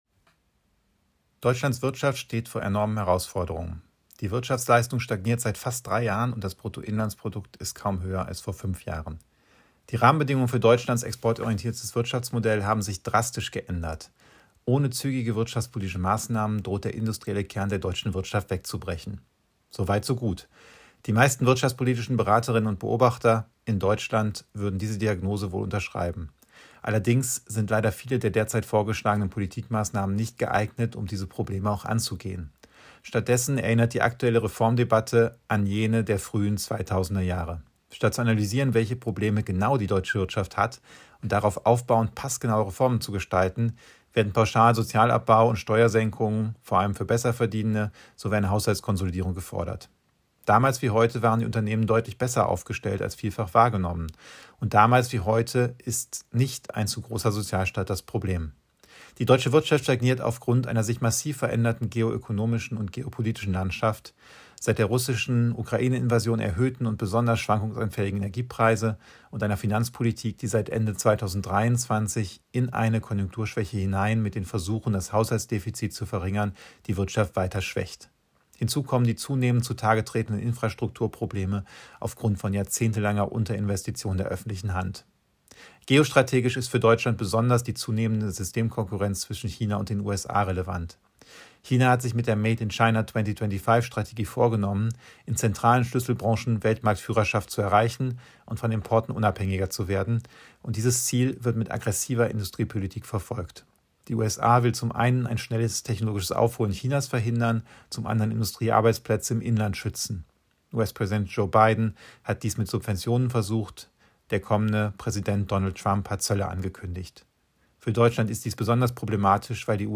Audiostatement